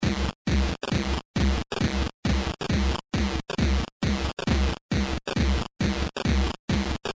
Tag: 100 bpm Blues Loops Guitar Electric Loops 413.48 KB wav Key : Unknown